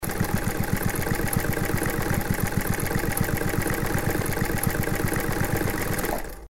Шум работающей швейной машинки